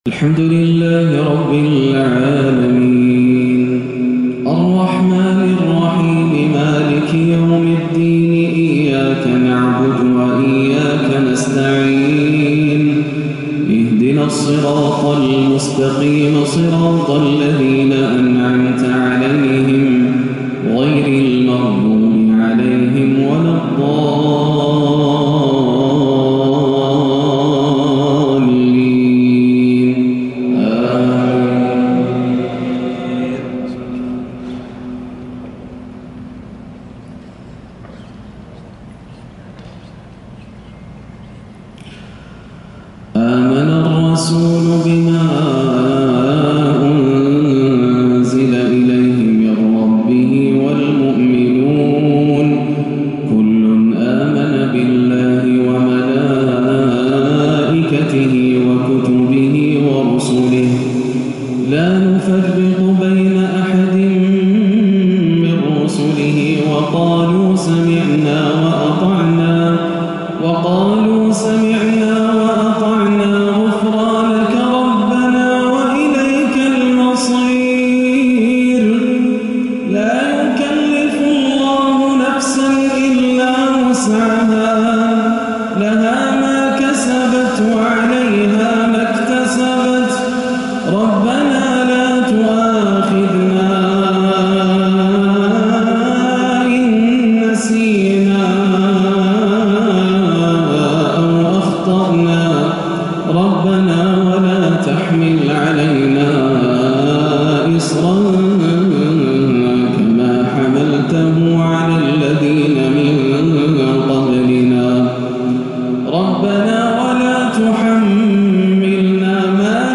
(ربنا لا تؤاخذنا إن نسينا أو أخطأنا) تلاوة خاشعة لأواخر البقرة وسورة الهمزة - مغرب الإثنين 9-1 > عام 1438 > الفروض - تلاوات ياسر الدوسري